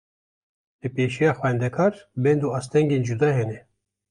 Pronounced as (IPA)
/xʷɛndɛˈkɑːɾ/